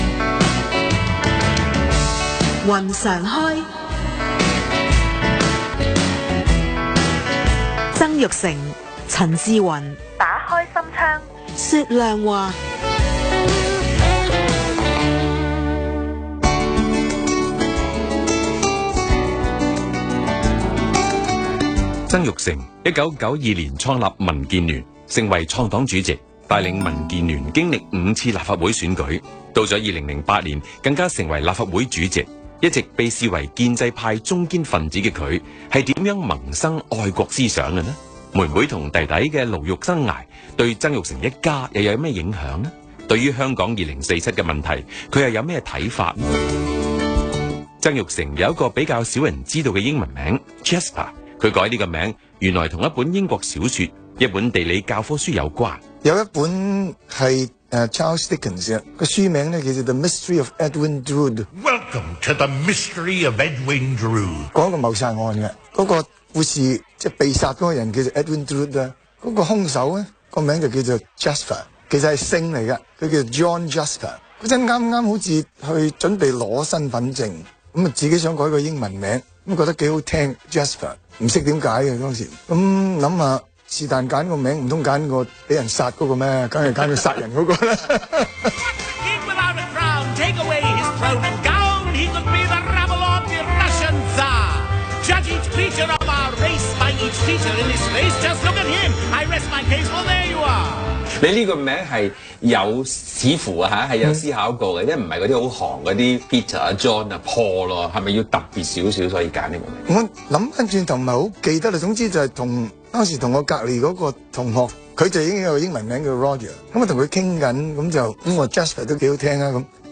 商業電台《雲常開》訪問-第一集
2016年5月9日 商業電台《雲常開》訪問 主持人：陳志雲